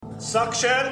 gachi suction
suction_lXUaIEQ.mp3